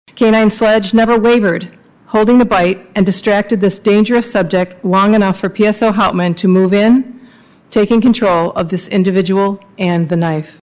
Vice Mayor Jeanne Hess read from the resolution.